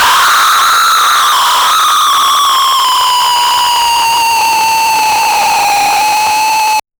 the sound of the appearance of the C ECHO. a very smoothly rising ECHO from silence to a loud state and abruptly ending with an ECHO consisting of the screams of a man's 8-bit style screams of abandonment and terror. 0:07 Created Apr 20, 2025 8:32 PM